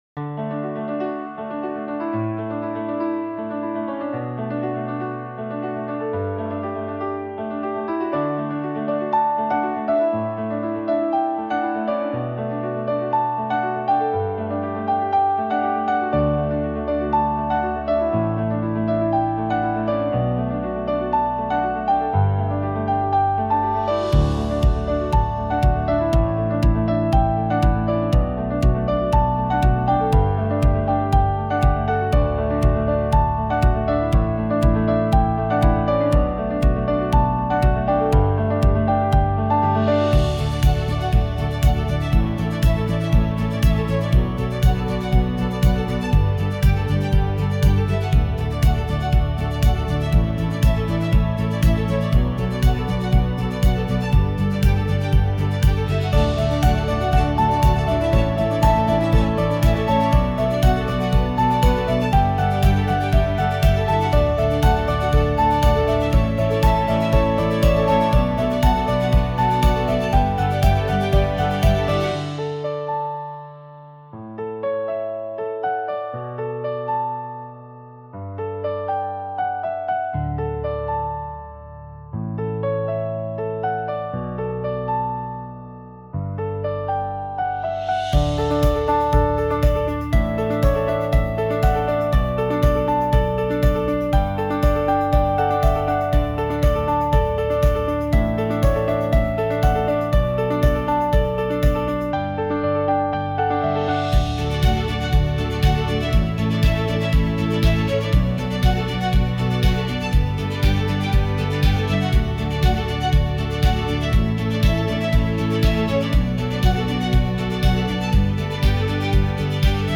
ビジネス・企業向け・インストゥルメンタル・ボーカル無し